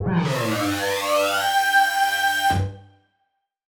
Index of /musicradar/future-rave-samples/Siren-Horn Type Hits/Ramp Up